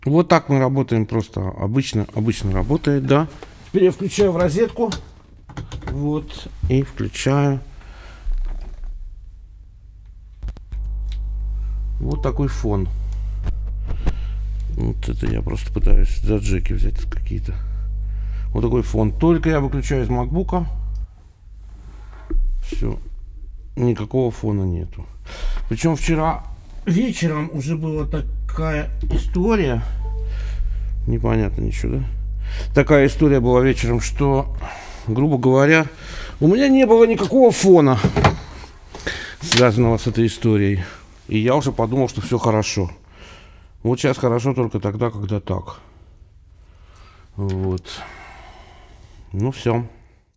Непонятный Фон в Apollo Solo USB
Подключаю микрофон конденсаторный ,для Записи голоса-и ...Такой фон ,как будто земли нет...Низкий.Рукой прикасаюсь к микрофону(к его железной части) чуть меньше.